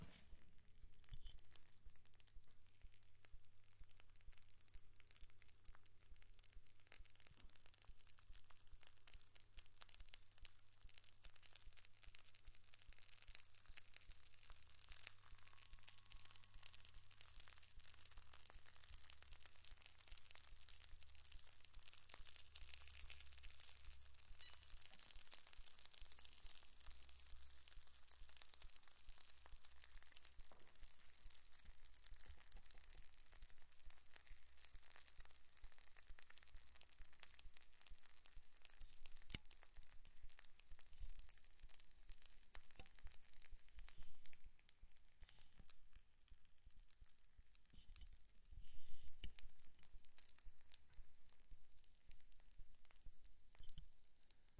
煎烤香肠
描述：炸香肠
Tag: 烹饪 现场录音 油炸 香肠 变焦-H2